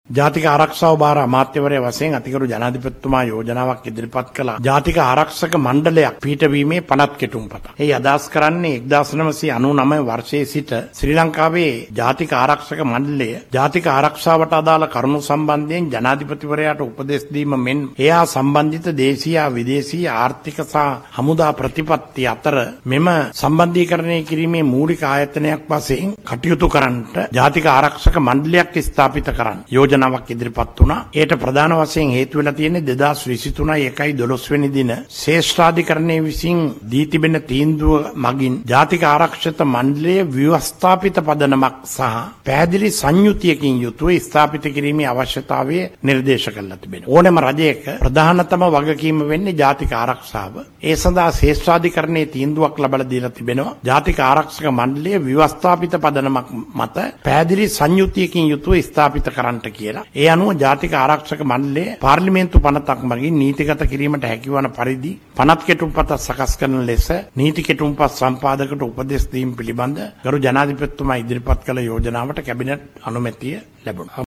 මේ සම්බන්ධයෙන් අද පැවති කැබිනට් තීරණ දැනුම් දීමේ මාධ්‍ය හමුවේදී කැබිනට් මාධ්‍ය ප්‍රකාශක බන්දුල ගුණවර්ධන මහතා අදහස් පළ කළා.